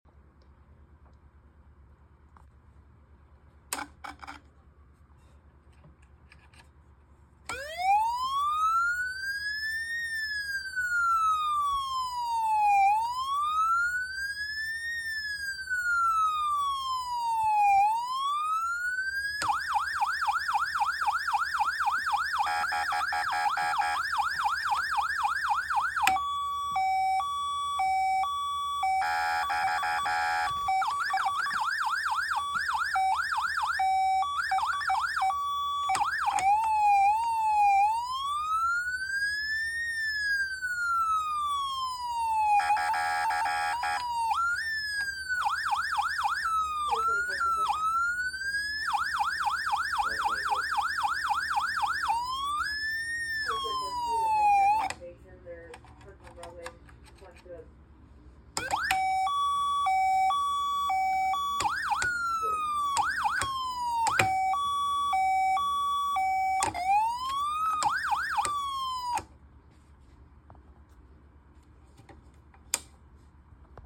Code 3 3692L4 V Con siren sound effects free download
Code 3 3692L4 V-Con siren Mp3 Sound Effect